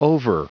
Prononciation du mot over en anglais (fichier audio)
Prononciation du mot : over